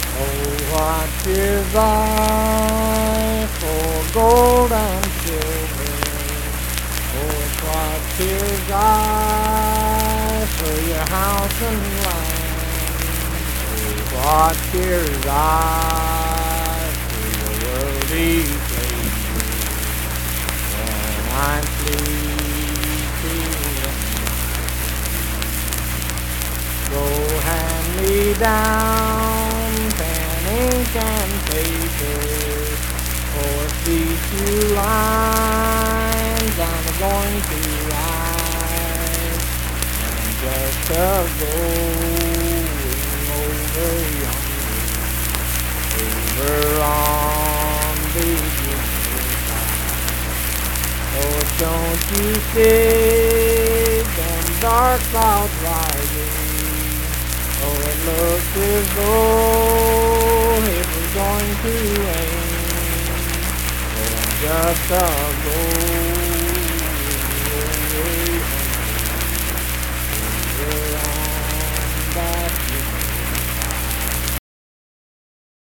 Unaccompanied vocal music
Voice (sung)
Saint Marys (W. Va.), Pleasants County (W. Va.)